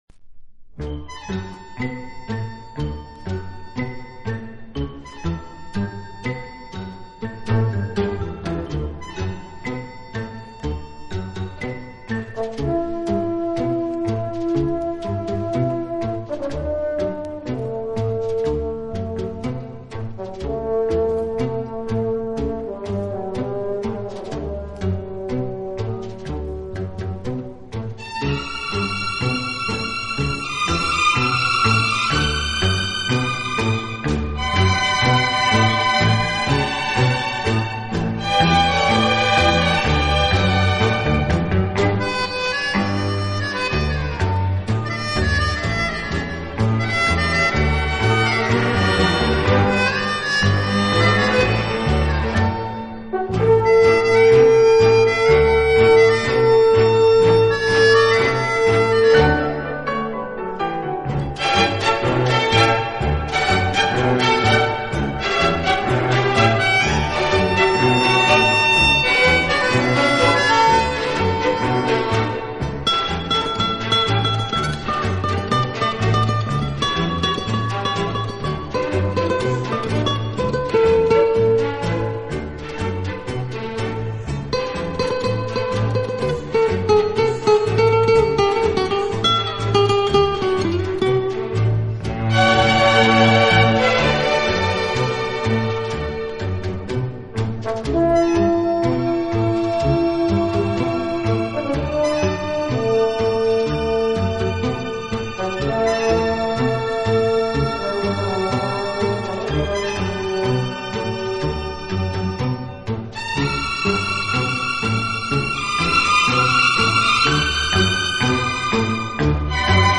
【轻音乐】
乐队以弦乐为中坚，演奏时音乐的处理细腻流畅，恰似一叶轻舟，随波荡